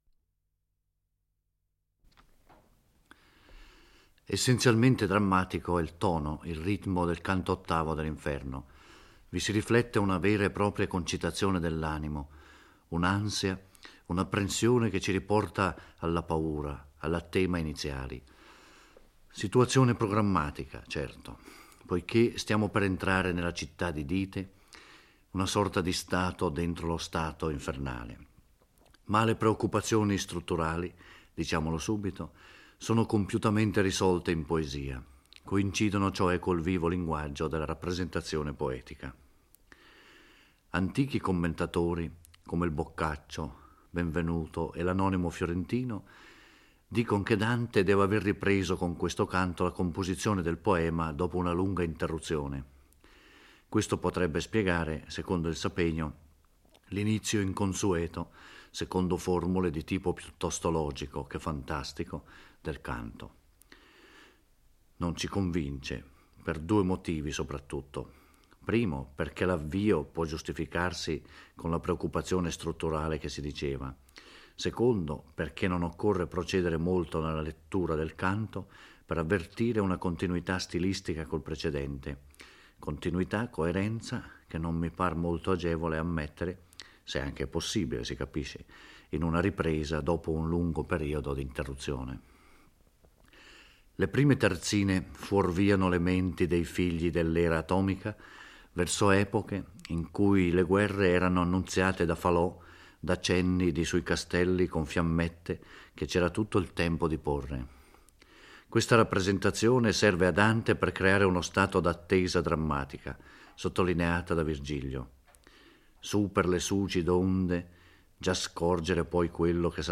Giorgio Orelli legge e commenta il canto VIII dell'Inferno, dove Dante e Virgilio giungono ai piedi di una torre costeggiando la riva dello Stige.